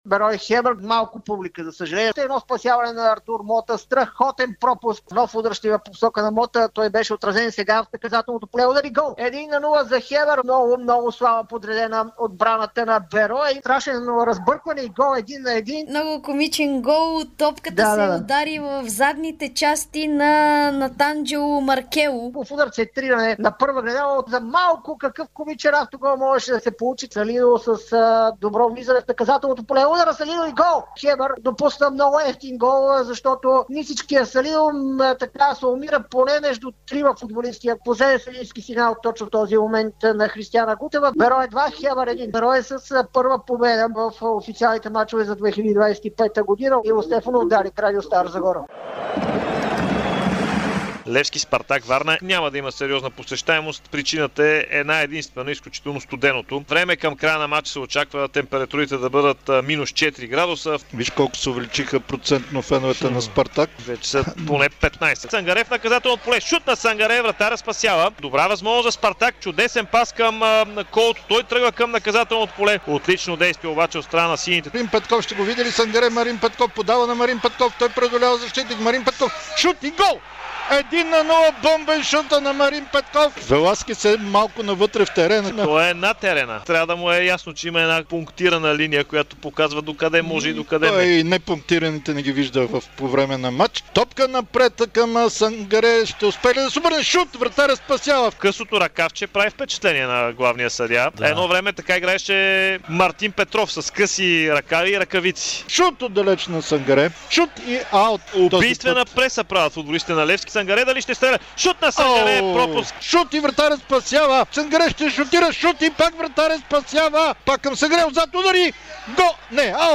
Какво чухме по Дарик от родните терени докато малцина зъзнаха по стадионите, кой на кого прекъсна любовната игра и още много от мачовете от 22-рия кръг на Първа лига с пропуските и головете през очите на коментаторите на Дарик радио!